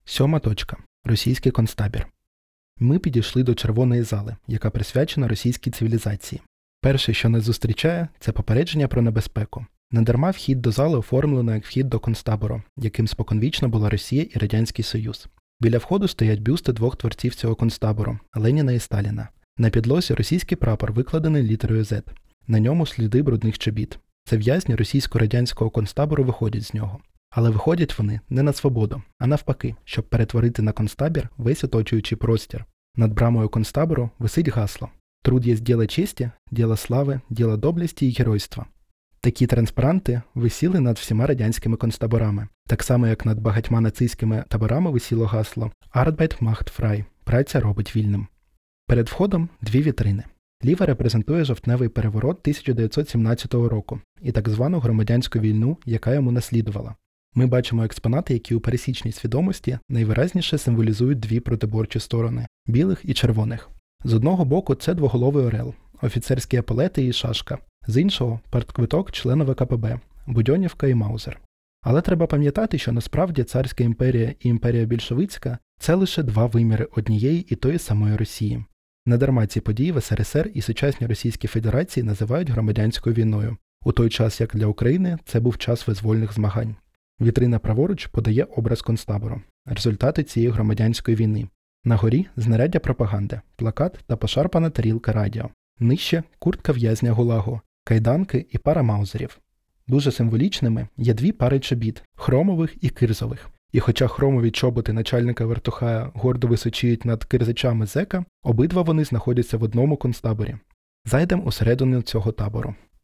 Аудіогід_7_точка_Російський_концтабір.wav